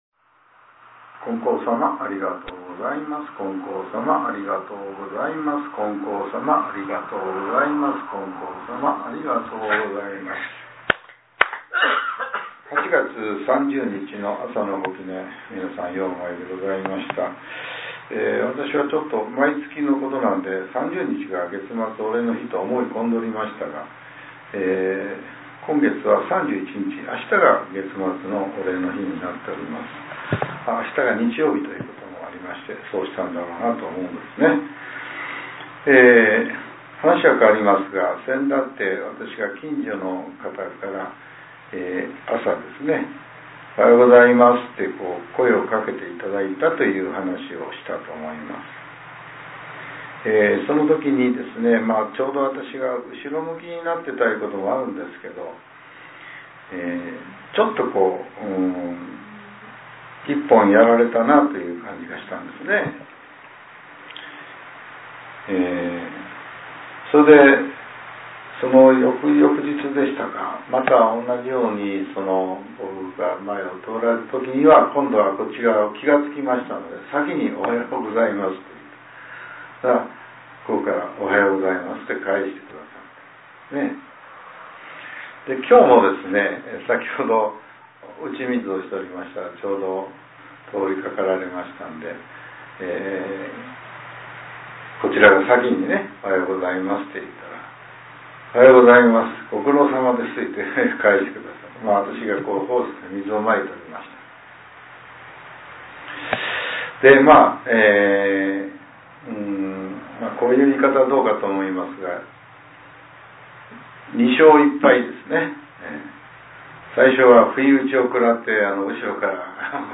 令和７年８月３０日（朝）のお話が、音声ブログとして更新させれています。